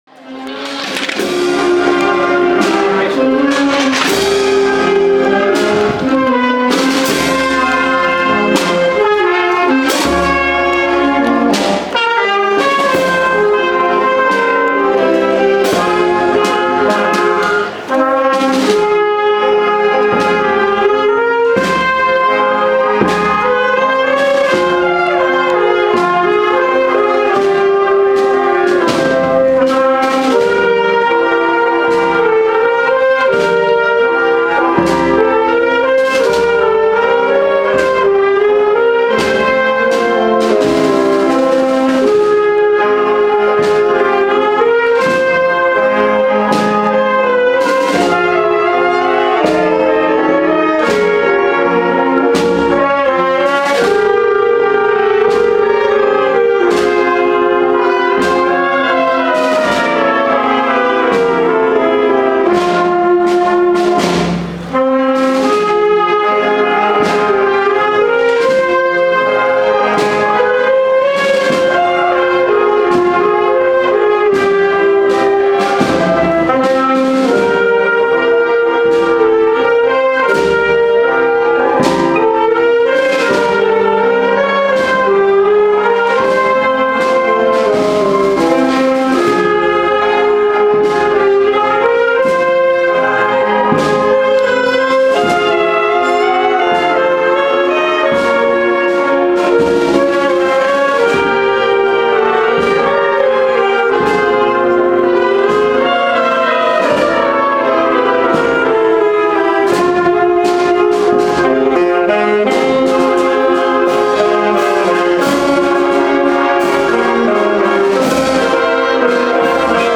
Procesión Santiago Apóstol, patrón de Totana - 2014
Autoridades municipales, civiles y religiosas, y cientos de personas acompañaron el pasado 25 de julio la imagen del patrón de Totana, Santiago Apóstol, en una solemne procesión, que estuvo portada a hombros por los hermanos de la Hermandad de Jesús en el Calvario y Santa Cena.